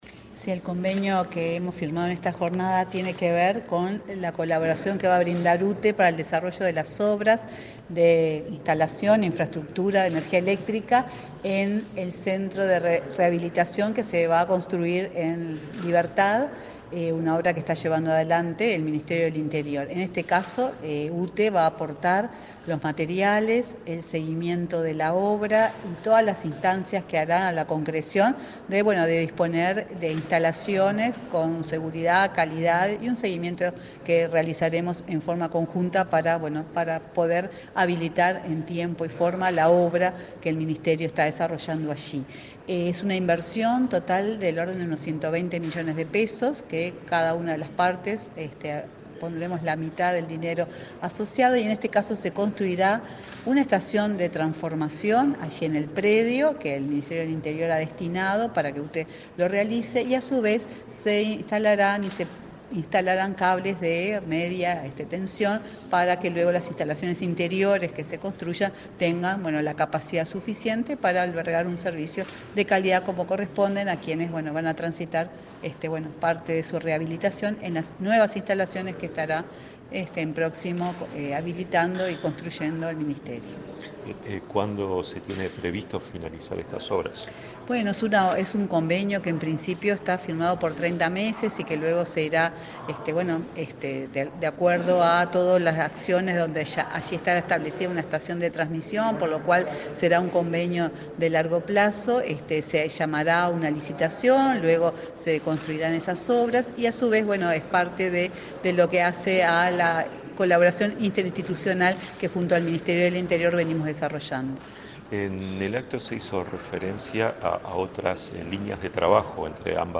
Declaraciones de la presidenta de UTE, Silvia Emaldi
Declaraciones de la presidenta de UTE, Silvia Emaldi 31/07/2024 Compartir Facebook X Copiar enlace WhatsApp LinkedIn La presidenta de la UTE, Silvia Emaldi, dialogó con la prensa, luego de firmar un acuerdo con autoridades del Ministerio del Interior, para ejecutar obras para el suministro eléctrico a un nuevo módulo del Instituto Nacional de Rehabilitación, en el penal de Libertad, departamento de San José.